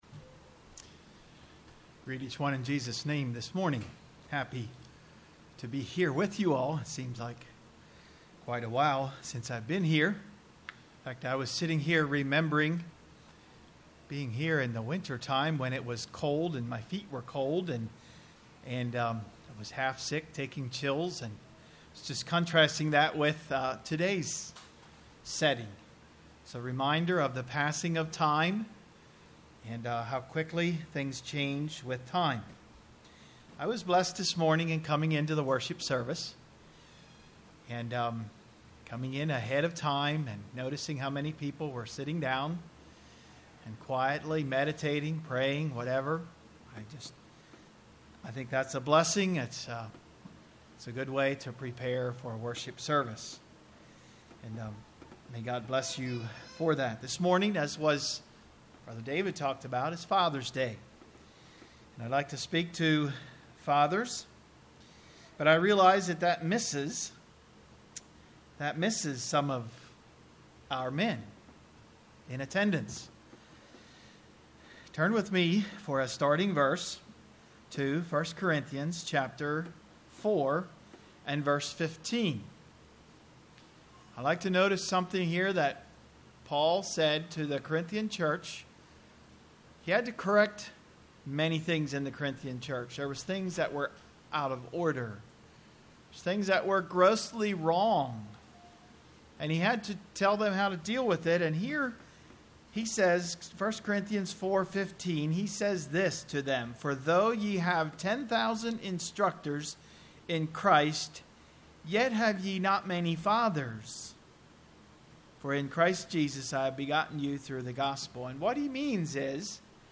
A Father's Day sermon. The text was Joshua 1:6-9.